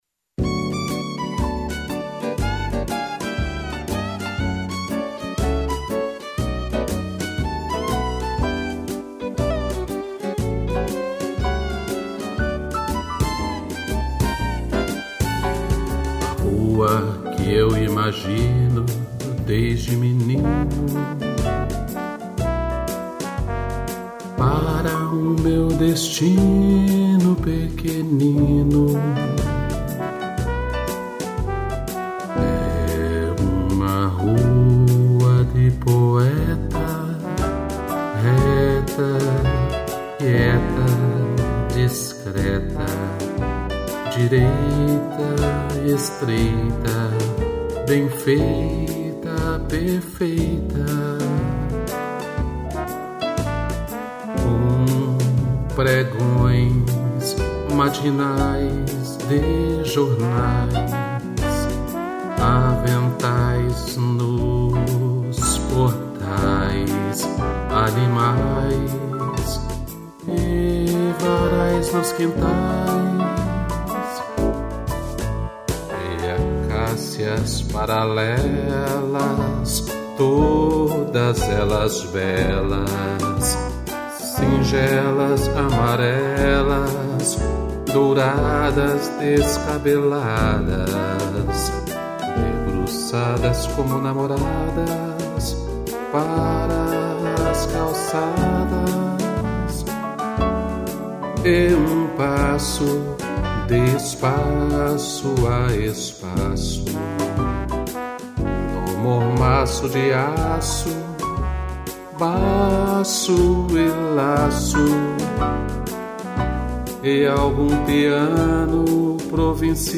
voz
piano e trombone